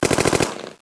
Thompson Submachine Gun
Selective Fire (Semi-automatic & Fully-automatic)